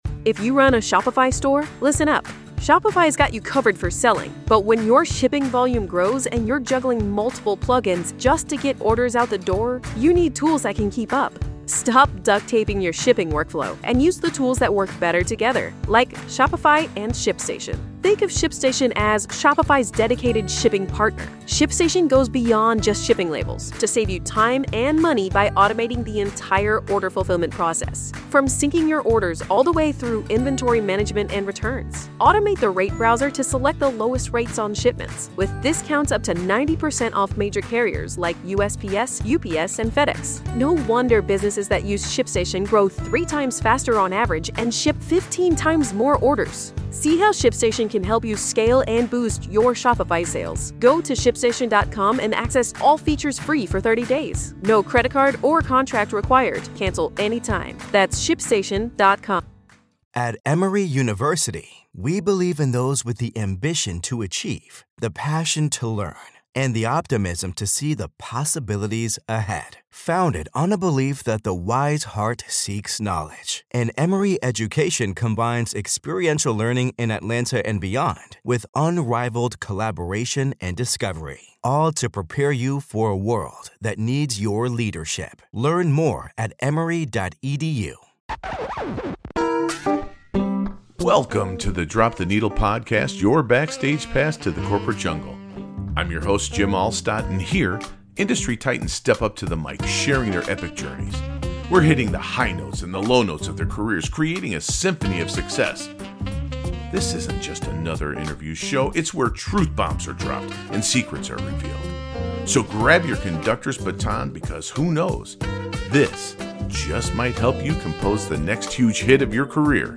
The conversation concludes with a musical segment exploring J